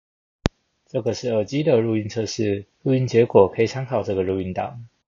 ▶ 無背景音樂錄音檔
● 耳機收音